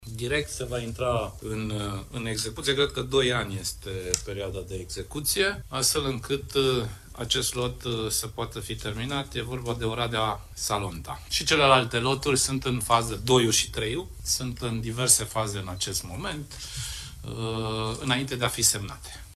15mar-13-Grindeanu-despre-drumul-Expres-Arad-–-Oradea-.mp3